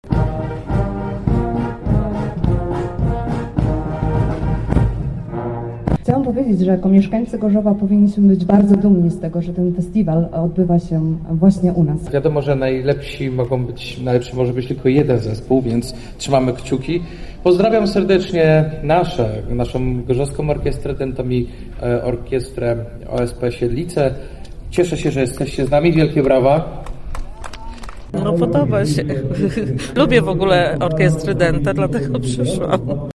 Tegoroczny festiwal uroczyście rozpoczął się o godzinie 15.00 na placu Gorzowskiego Rynku Hurtowego.
Wszystkie orkiestry wspólnie odegrały utwór „Alte Kameraden”